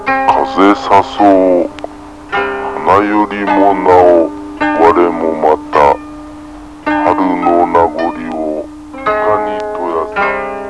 と次々に歴史上の人物達の語りかけです。